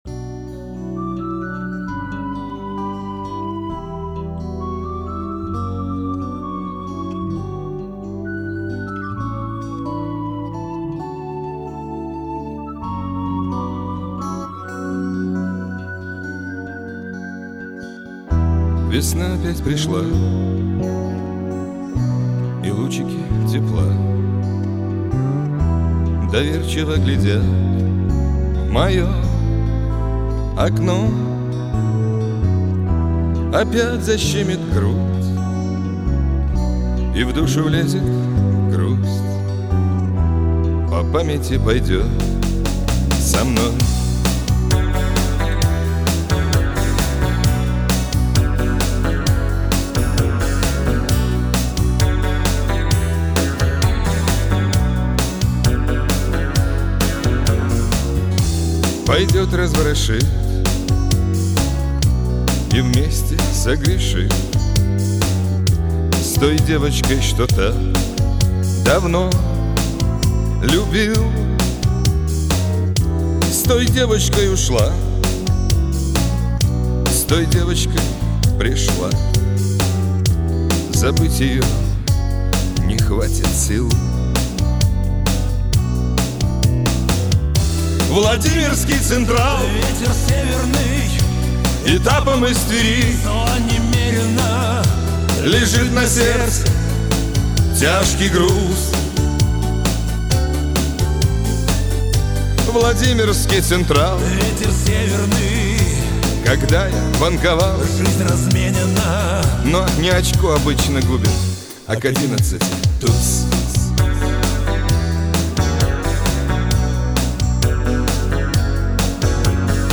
您当前位置：网站首页 > 香颂（шансон）界